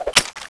draw_b.wav